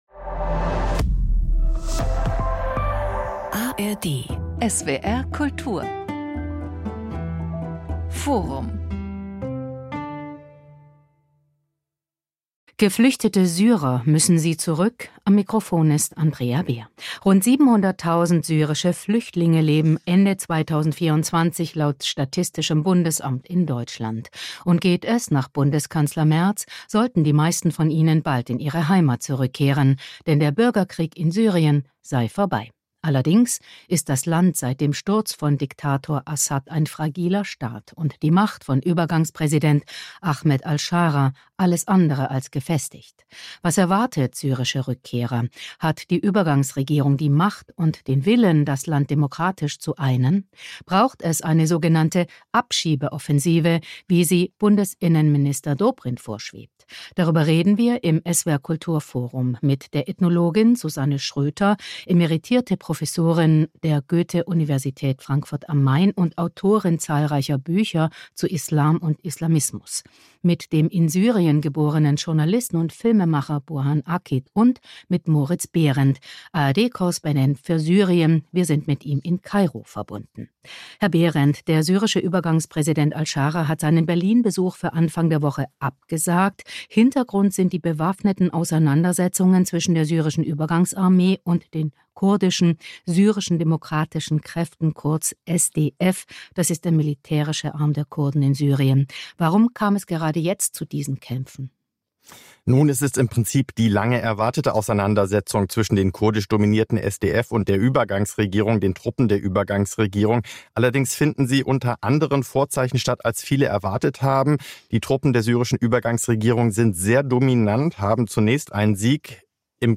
Journalist und Filmemacher
Dr. Susanne Schröter - Ethnologin und Autorin Mehr